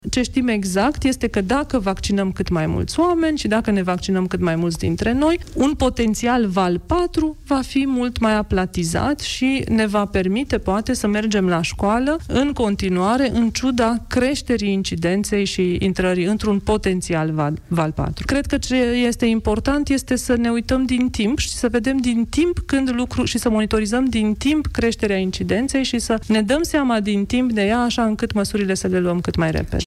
Dacă vor fi și mai mulți oameni imunizați, un eventual val patru al pandemiei nu va fi atât de agresiv ca cele de până acum, a spus ministrul Sănătății – Ioana Mihailă…la emisiunea Piața Victoriei.